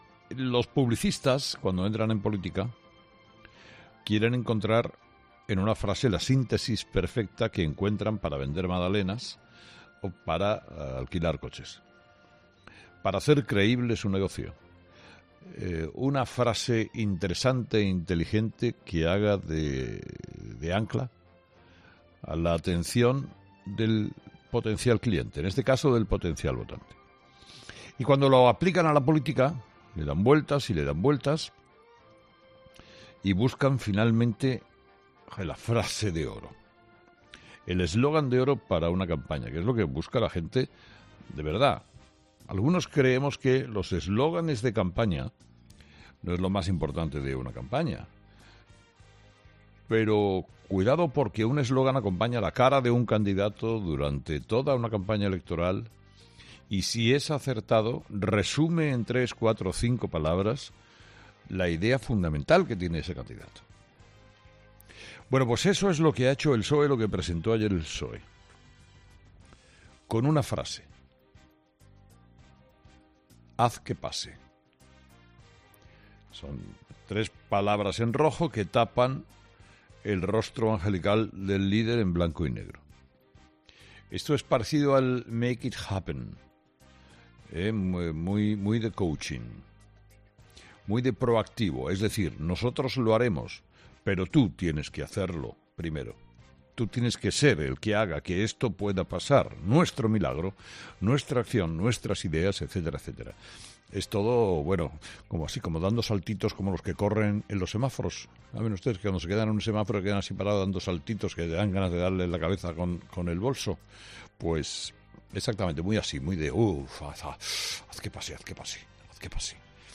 Carlos Herrera ha empezado su programa de este miércoles 3 de abril de 2019 analizando el eslógan de campaña que ayer hizo público el PSOE, con Pedro Sánchez como principal protagonista.